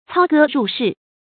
注音：ㄘㄠ ㄍㄜ ㄖㄨˋ ㄕㄧˋ
操戈入室的讀法